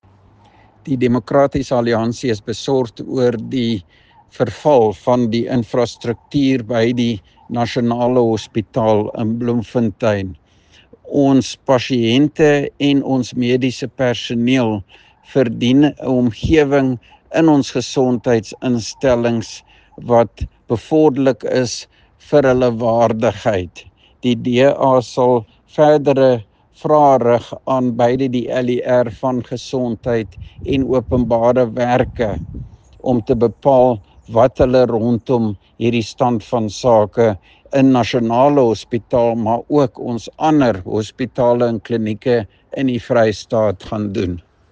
Afrikaans soundbite by Roy Jankielsohn MPL